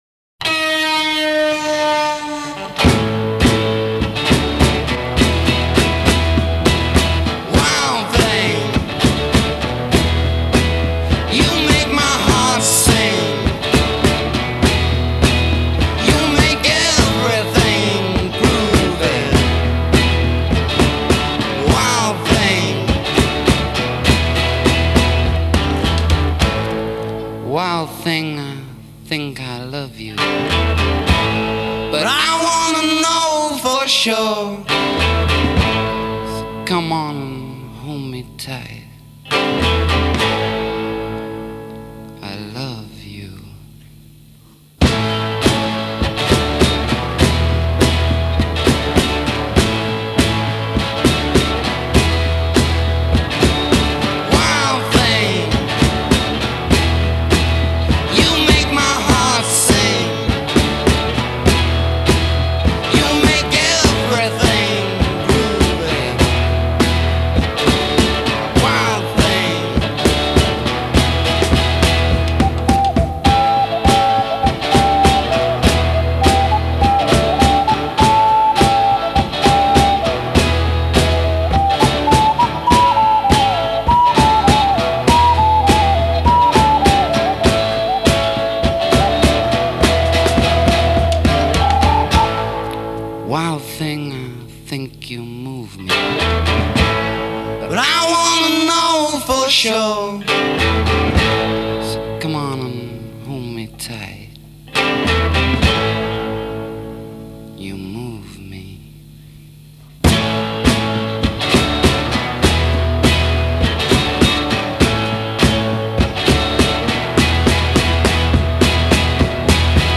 rock music